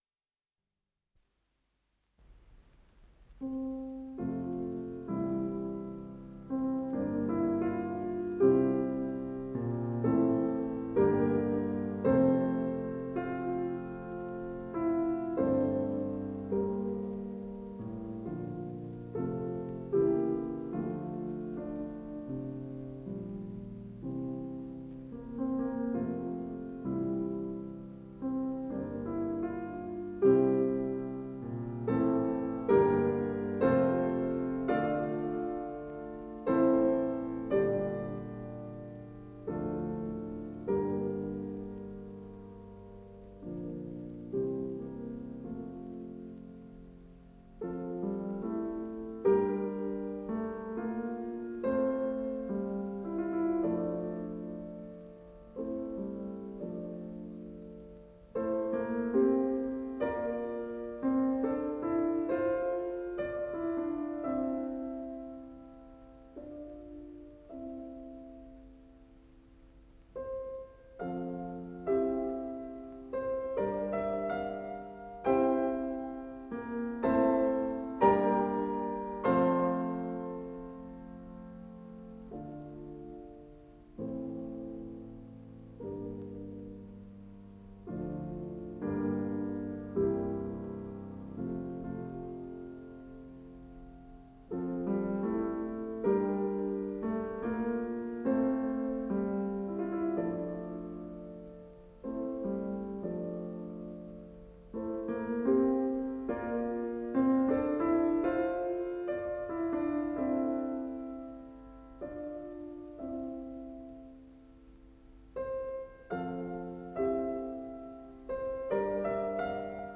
Исполнитель: Венское Шуберт-трио
"Элегическое трио", ре минор